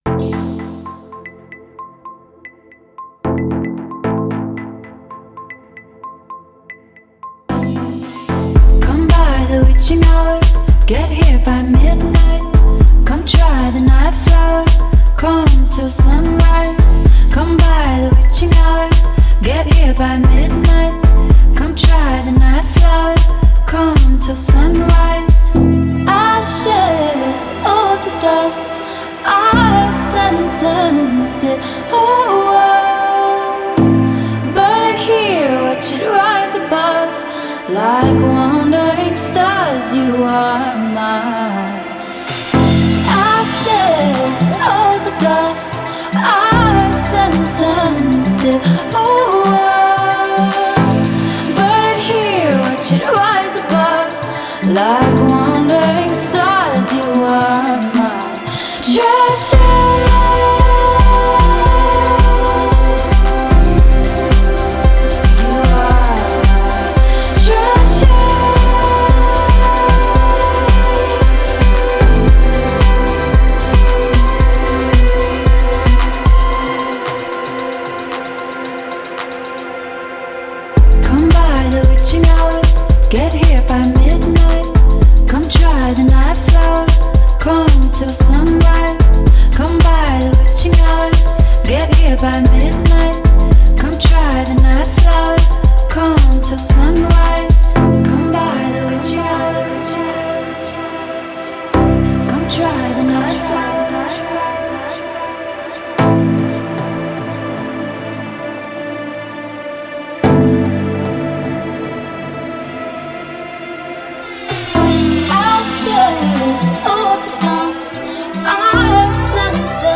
Genre: Pop, Singer-Songwriter